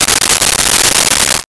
Plastic Wrapper 01
Plastic Wrapper 01.wav